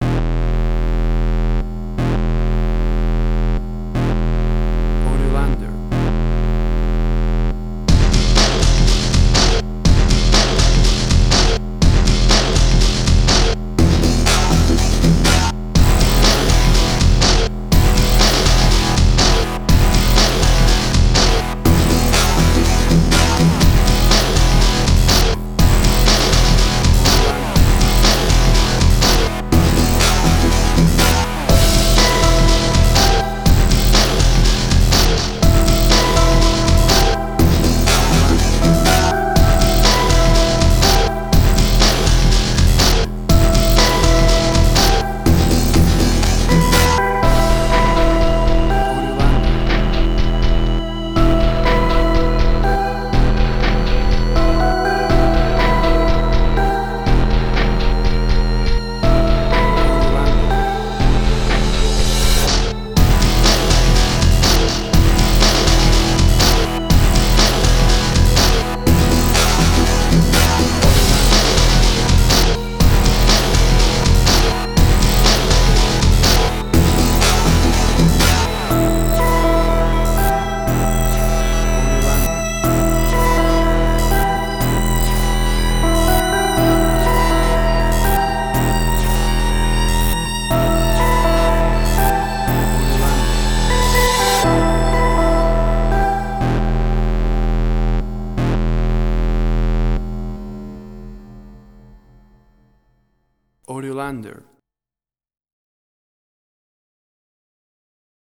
Suspense, Drama, Quirky, Emotional.
Tempo (BPM): 122